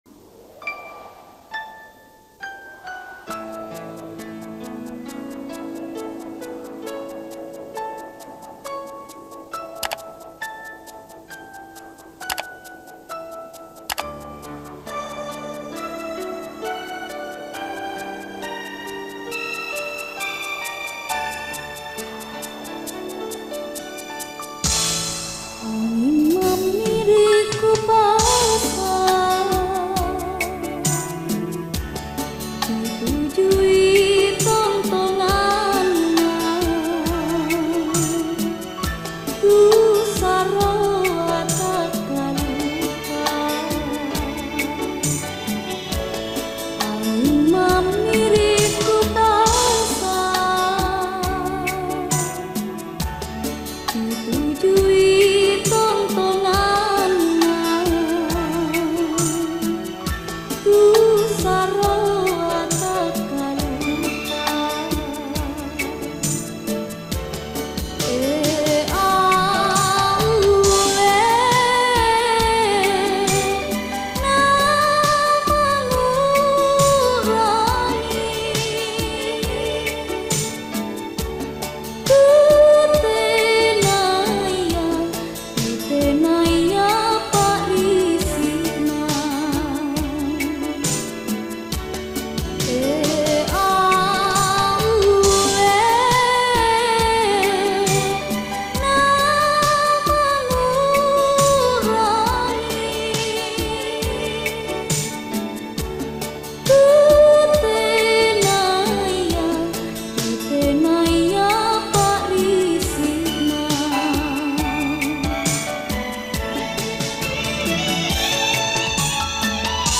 Malaysian, Bajau Borneo Old Folk Song